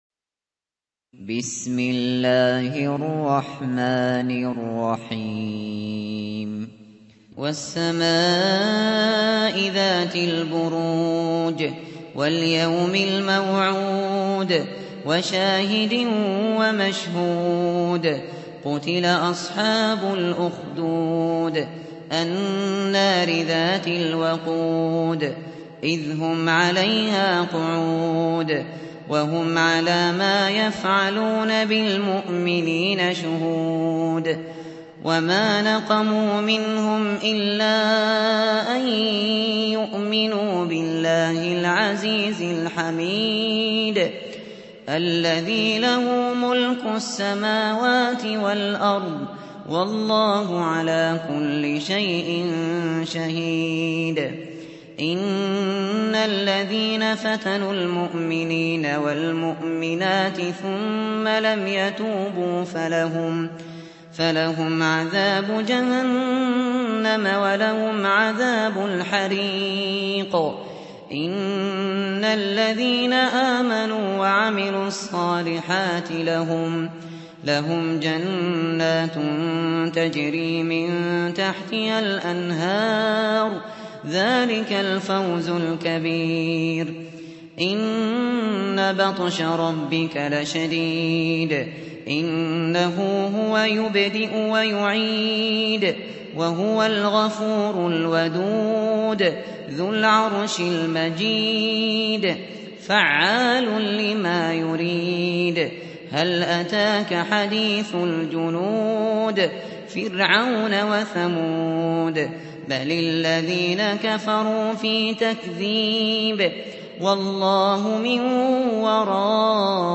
Récitation par Abu Bakr Al Shatri